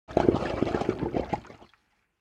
جلوه های صوتی
دانلود صدای آتشفشان 6 از ساعد نیوز با لینک مستقیم و کیفیت بالا
برچسب: دانلود آهنگ های افکت صوتی طبیعت و محیط دانلود آلبوم صدای آتشفشان از افکت صوتی طبیعت و محیط